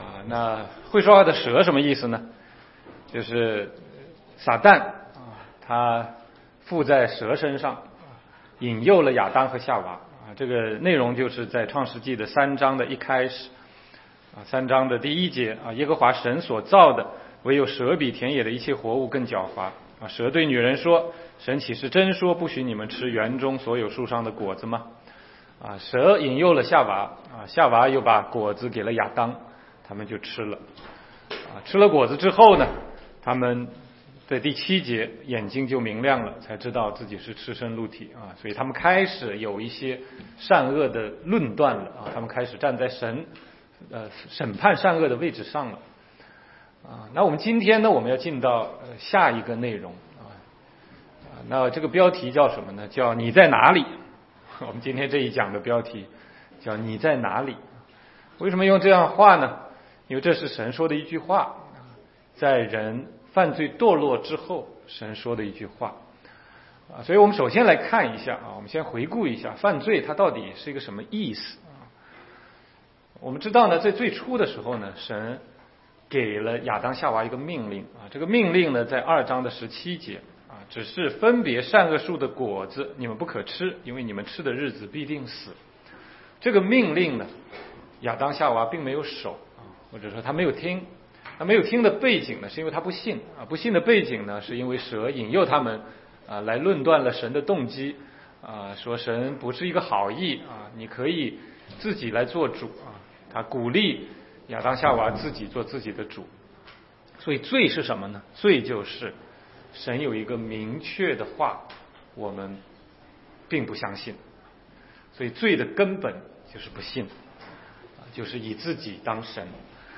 16街讲道录音 - 你在哪里？